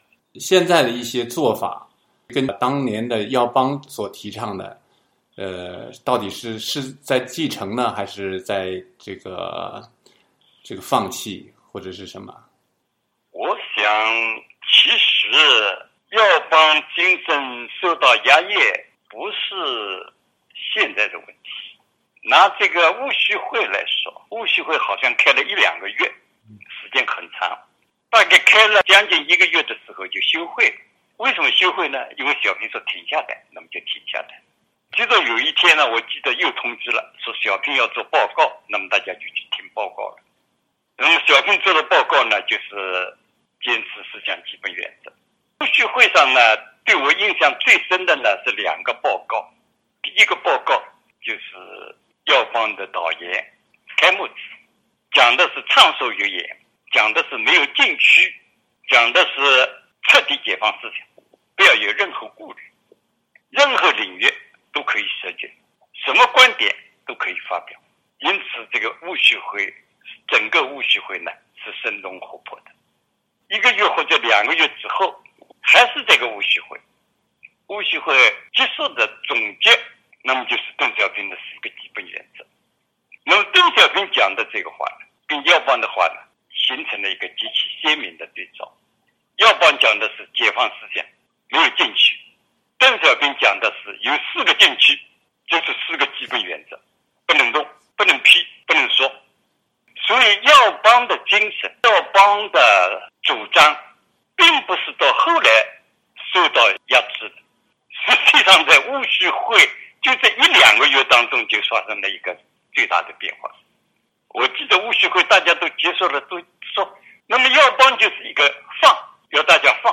（根据电话采访录音整理，受访者观点不代表美国之音）